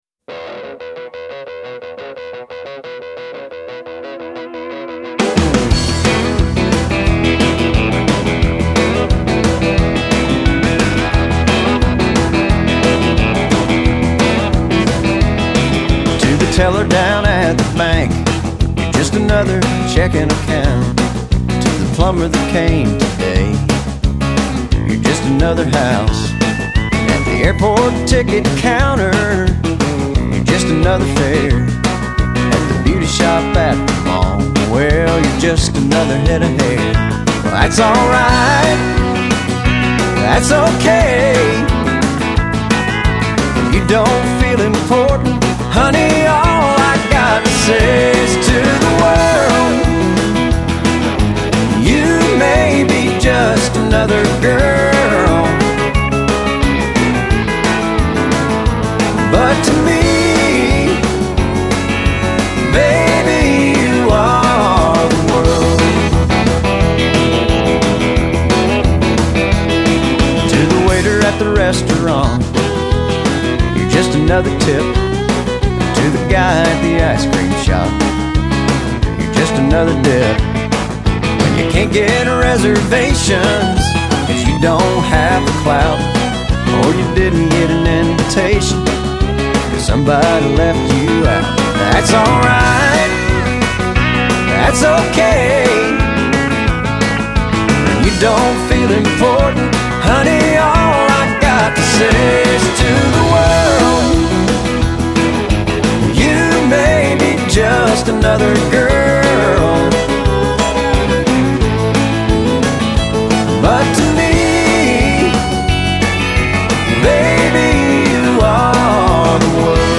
版本: 乡村音乐